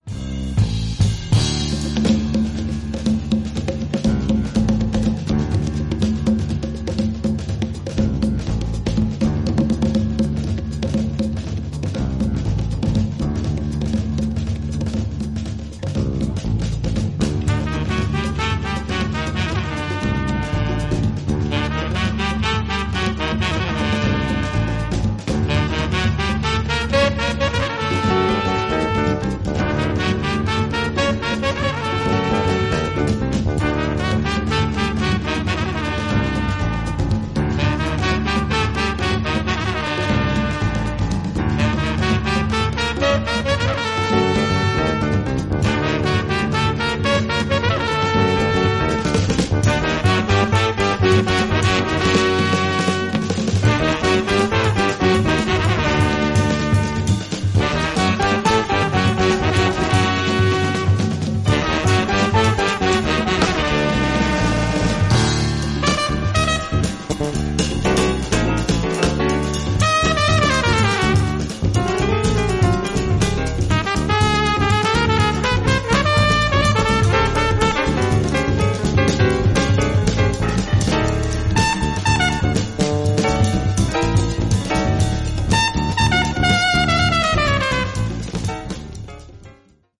リズミカルなトライバル・ジャズ